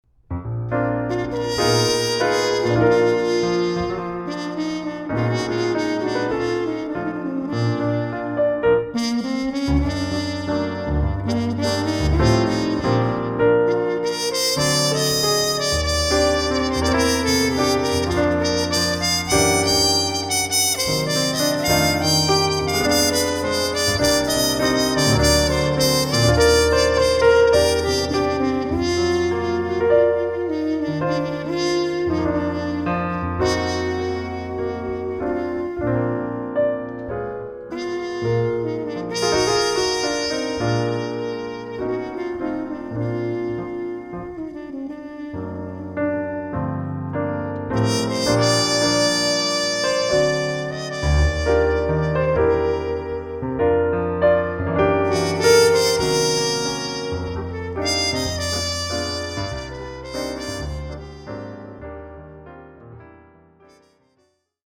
(piano)
(jazz waltz)
trumpet - vibes -